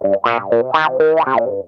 ITCH RIFF 2.wav